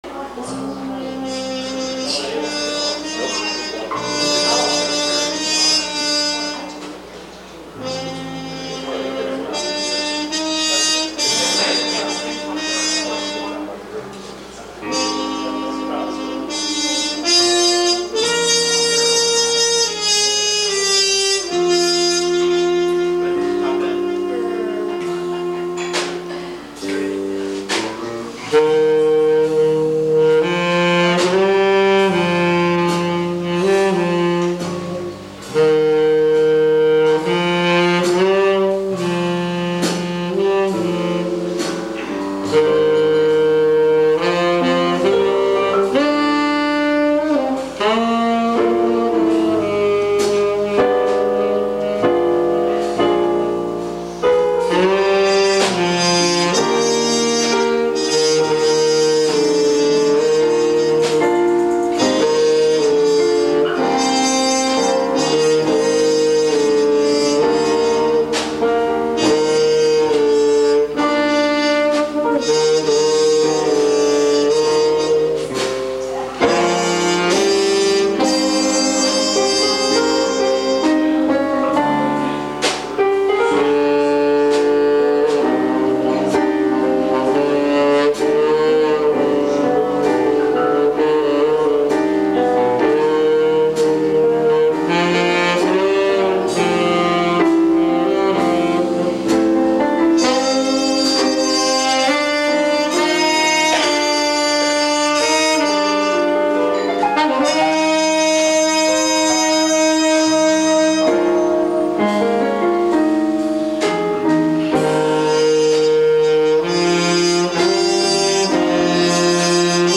tenor sax
trumpet
keyboards
bass
drums